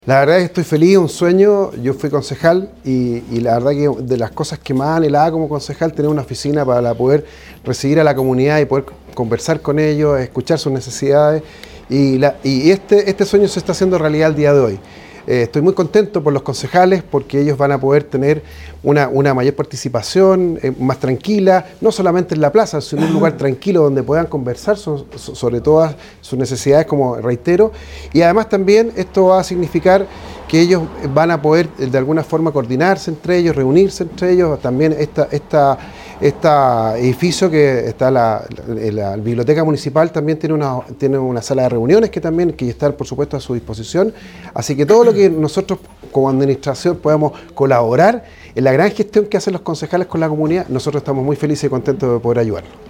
CUNA_ALCALDE_ROMERAL_SALACONCEJO.mp3